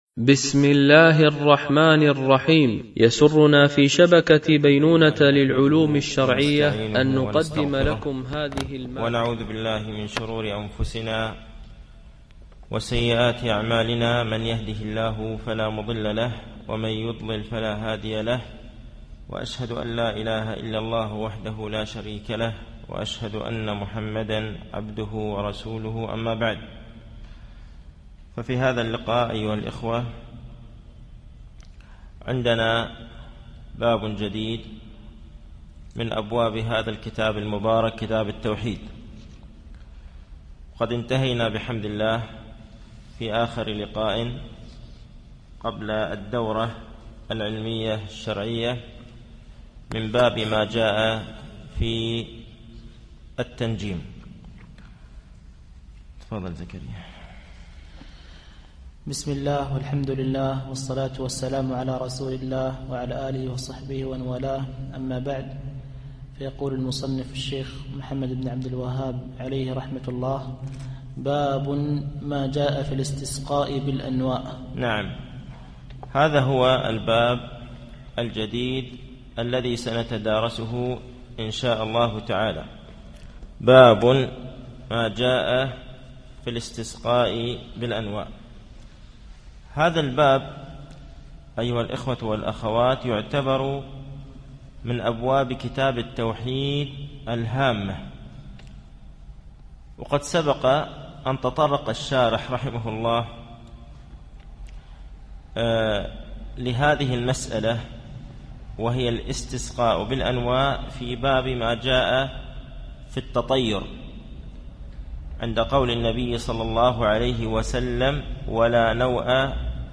التنسيق: MP3 Mono 22kHz 61Kbps (VBR)